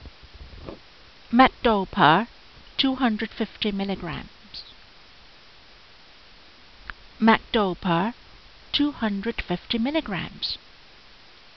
Pronunciation